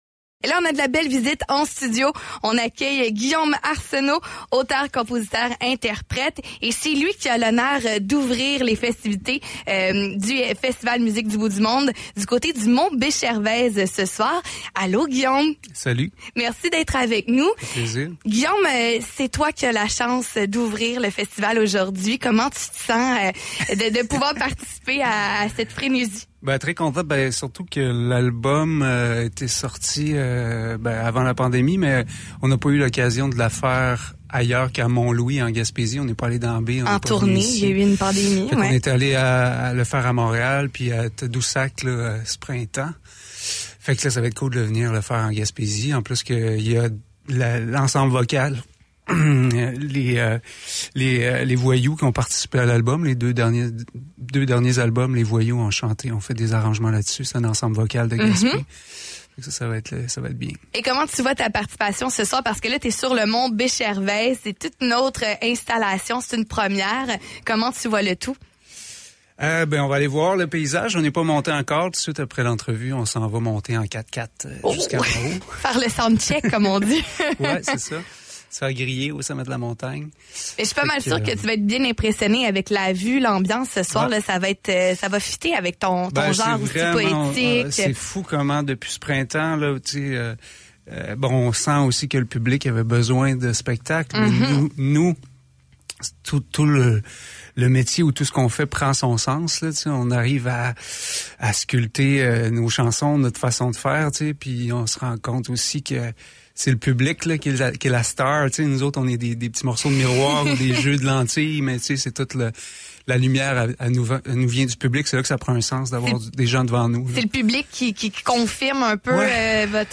l’a reçu en studio.